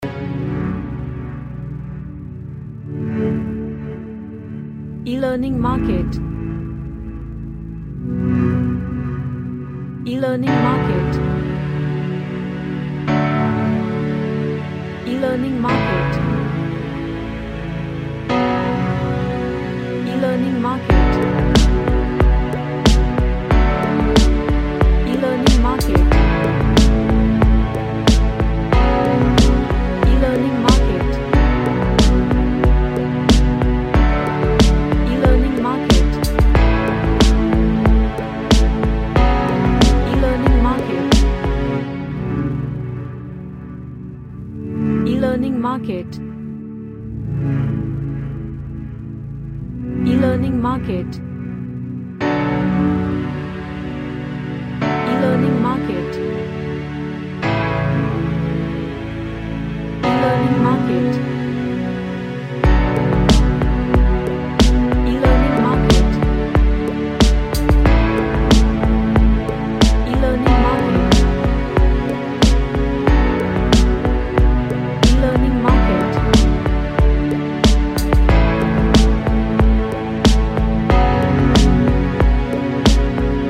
An Ambient track with lots of emotion to it.
Emotional